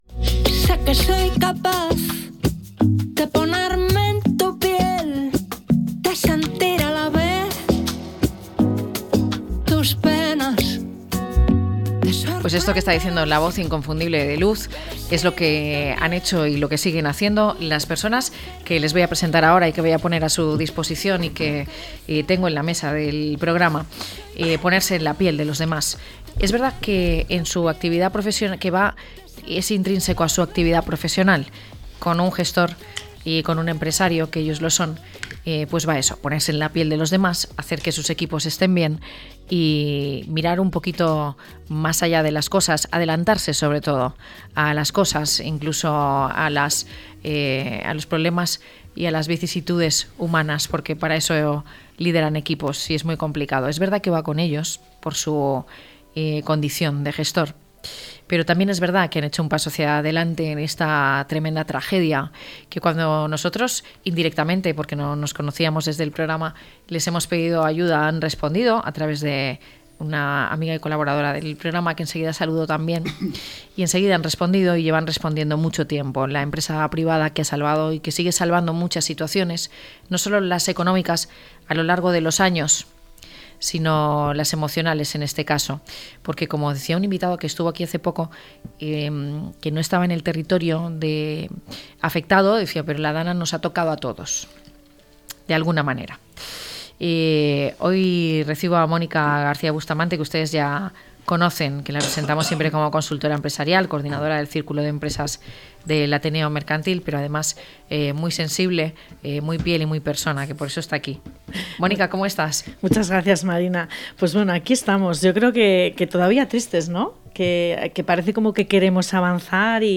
Tertulia empresarial